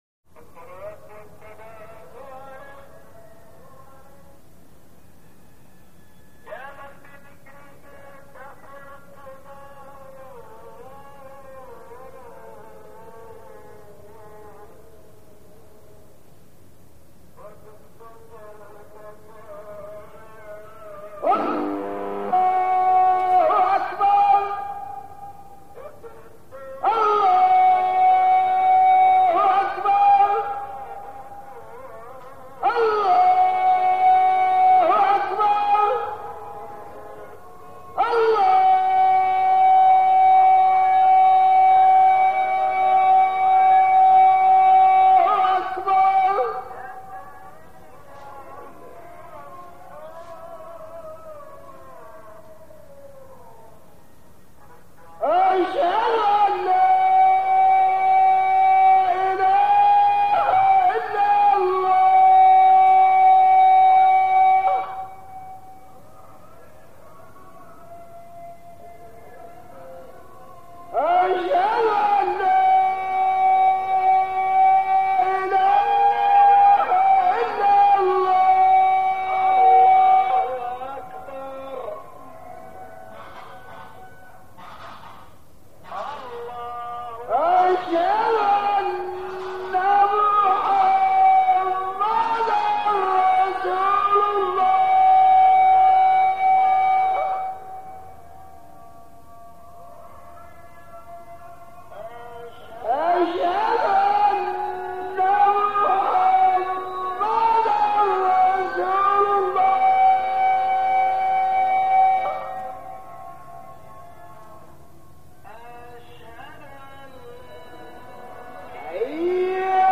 Abu-sir, Egypt - Bedouin Village Early Morning Atmosphere - Multiple Calls To Prayer, Chanting with Crude Amplification- Ambience, Middle East